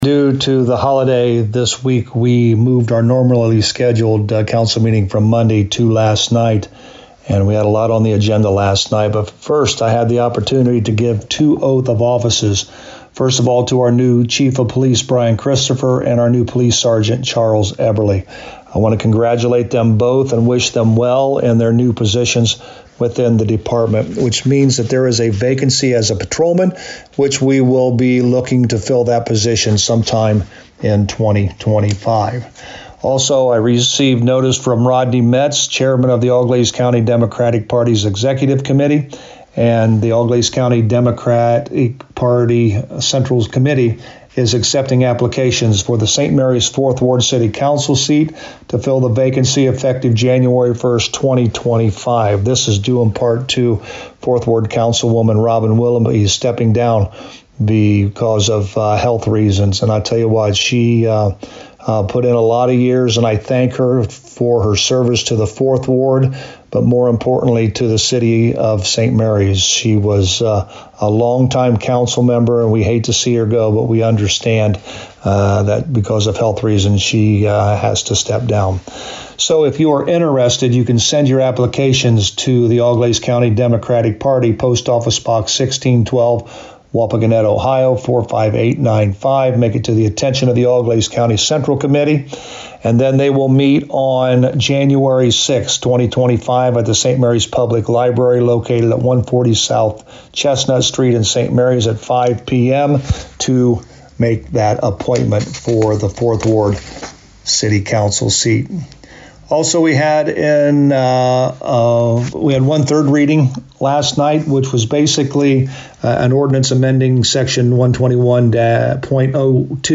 To hear a summary with St Marys Mayor Joe Hurlburt: